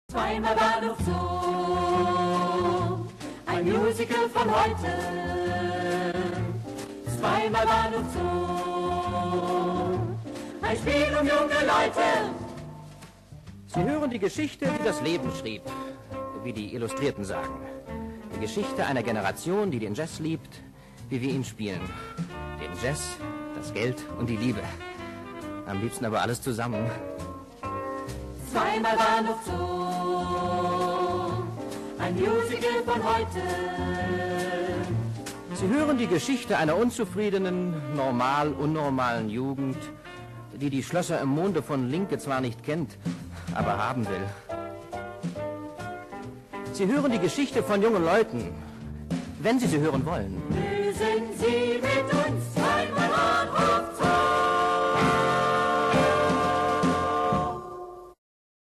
Rundfunkmusical (Hörspiel)